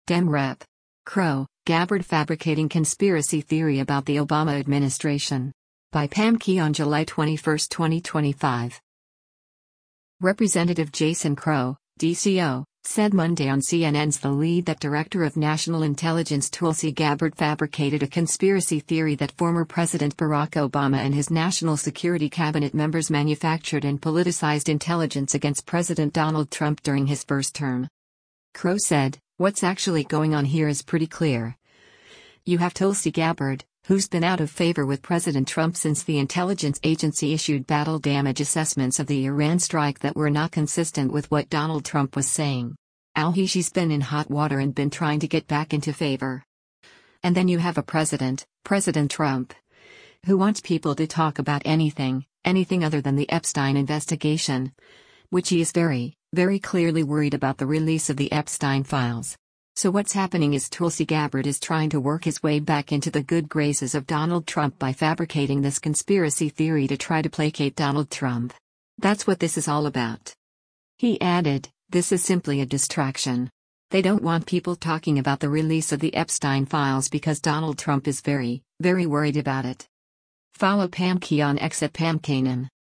Representative Jason Crow (D-CO) said Monday on CNN’s “The Lead” that Director of National Intelligence Tulsi Gabbard fabricated a “conspiracy theory” that former President Barack Obama and his national security cabinet members manufactured and politicized intelligence against President Donald Trump during his first term.